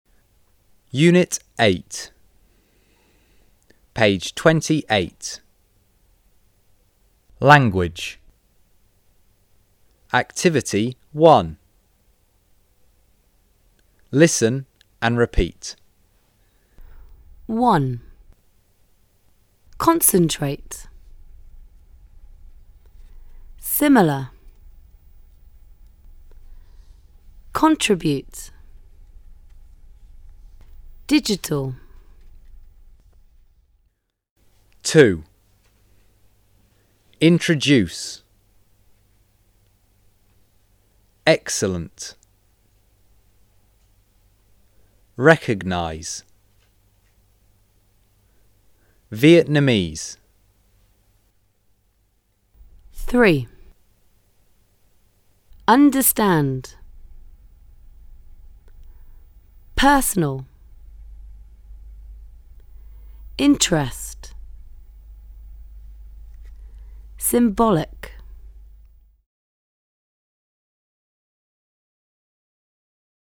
Sách nói | Tiếng Anh 10_Tập 2 (Unit 8)